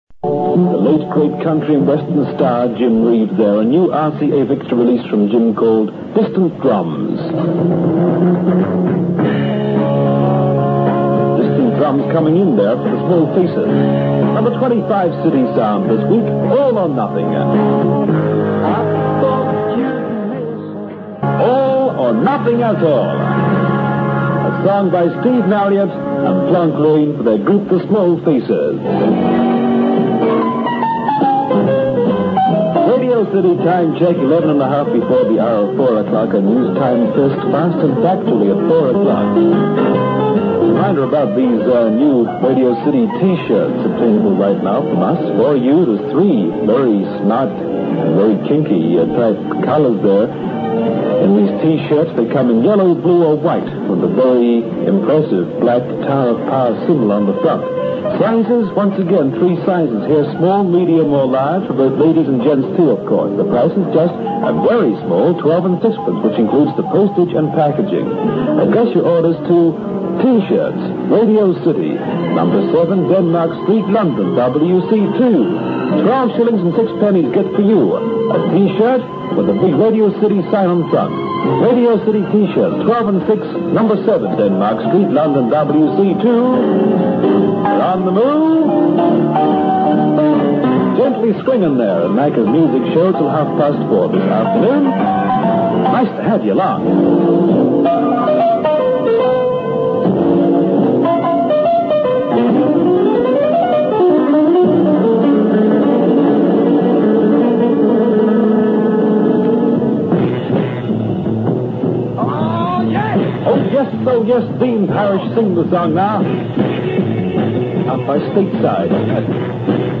on the air from August 1966